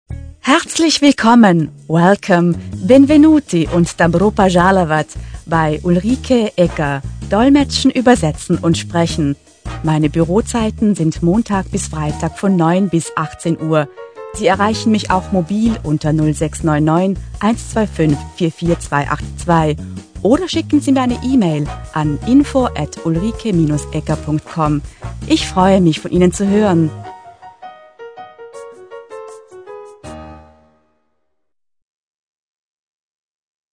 • AUDIOPROBE: Professionelle Telefonansage
professionelle-telefonansage.mp3